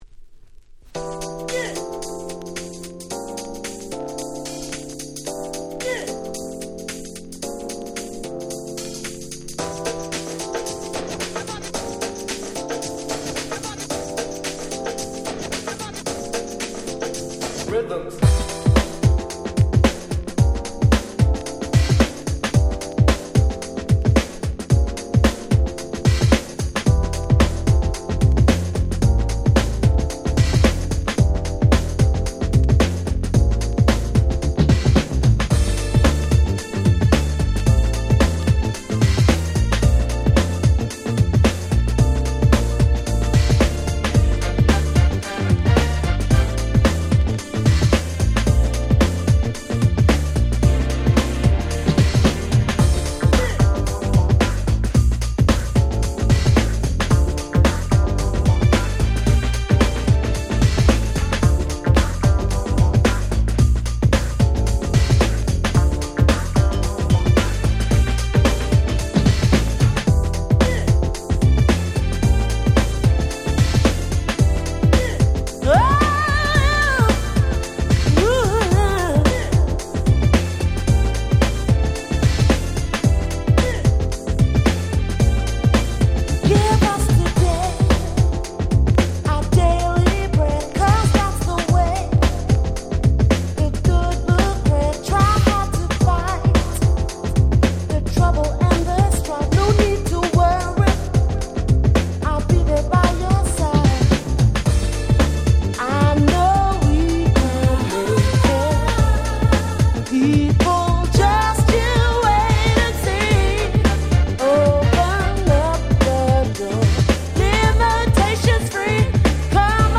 90's UK Soul UK R&B